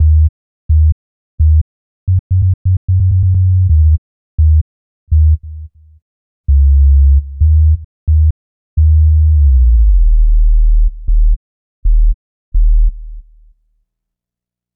Bass 48.wav